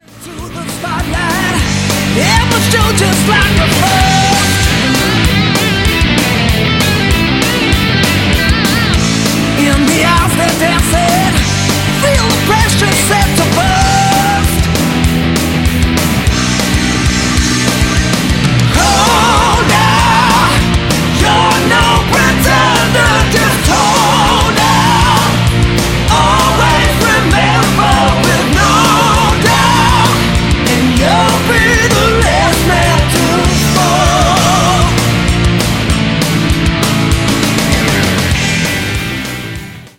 Guitars, Bass & Keyboards
Drums
Recorded at: Ocean Reach Studio's Runcorn, Cheshire, UK.